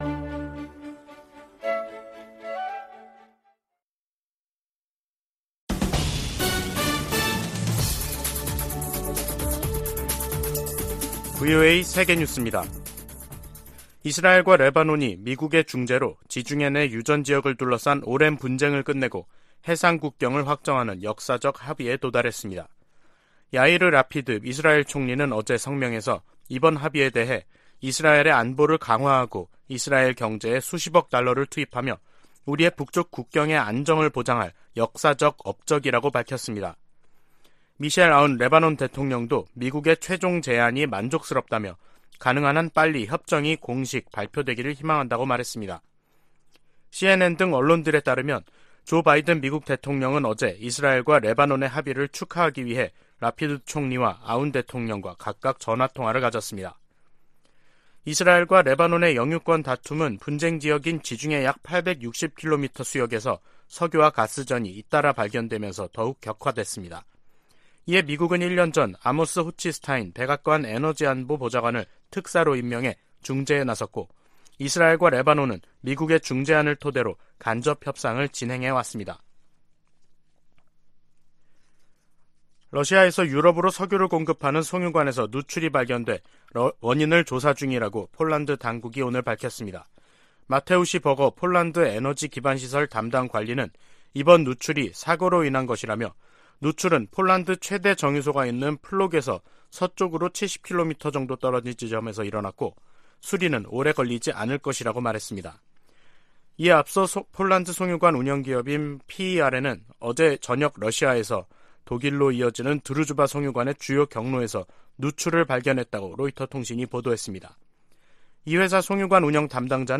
VOA 한국어 간판 뉴스 프로그램 '뉴스 투데이', 2022년 10월 12일 2부 방송입니다. 한국 일각에서 전술핵 재배치 주장이 나오는 데 대해 백악관 고위 당국자는 비핵화 목표를 강조했습니다. 북한이 최근 '전술핵 운용부대 훈련'을 전개했다며 공개한 사진 일부가 재활용된 것으로 파악됐습니다. 미국 정부가 핵을 포함한 모든 범위의 확장 억지 공약을 재확인했습니다.